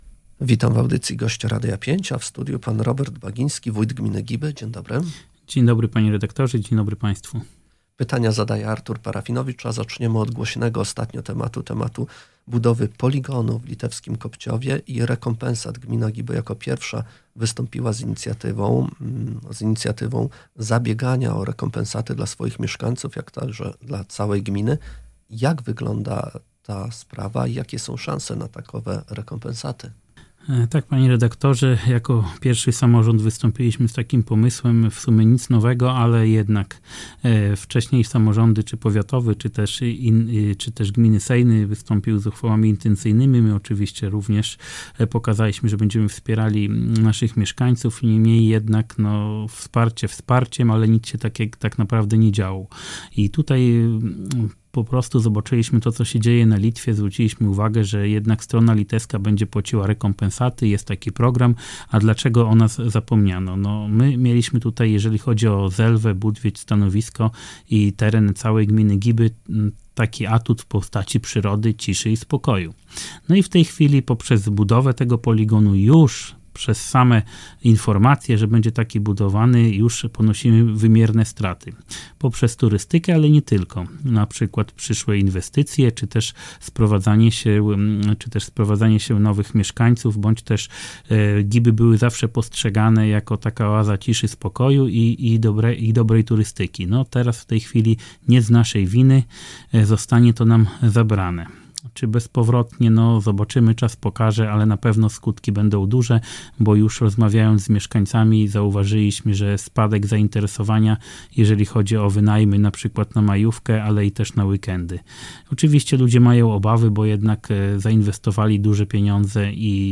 – W najgorszym przypadku złożymy pozew zbiorowy, ale to opcja atomowa – mówi Robert Bagiński, wójt gminy Giby, który zabiega o rekompensaty dla mieszkańców poszkodowanych przez planowana budowę poligonu w litewskim Kopciowie.